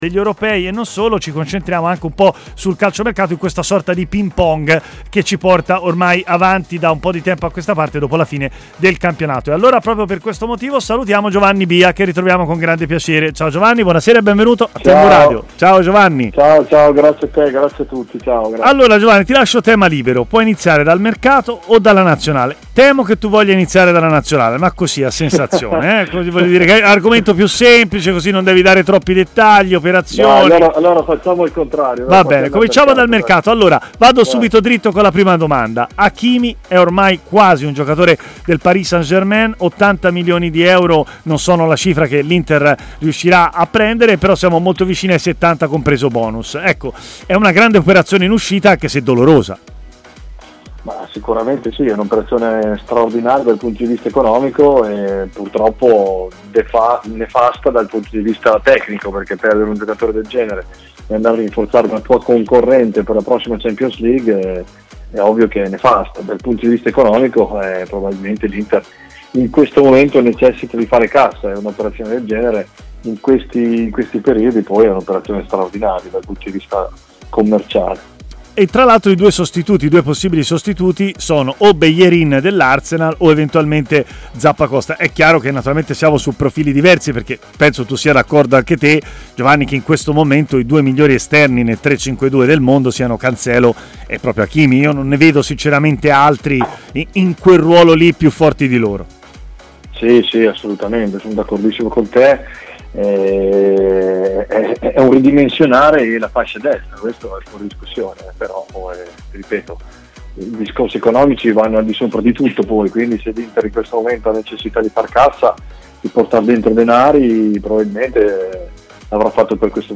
è intervenuto in diretta a Stadio Aperto, trasmissione di TMW Radio